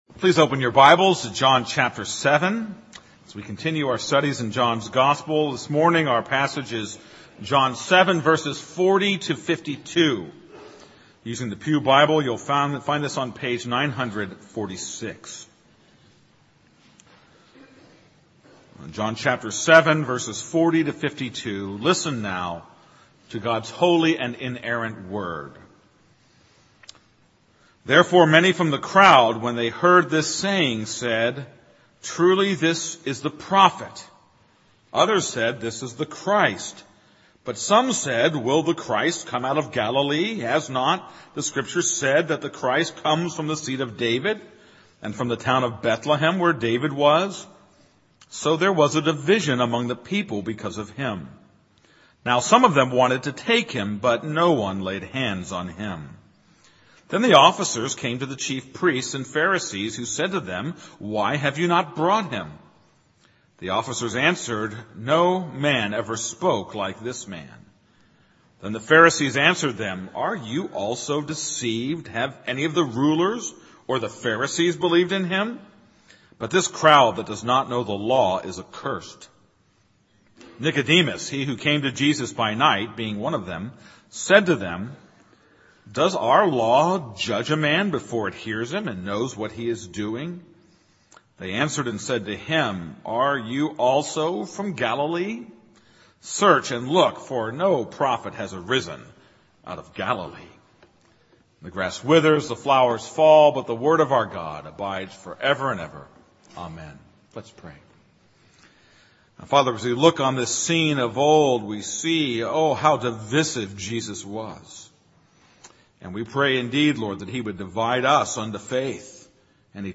This is a sermon on John 7:40-52.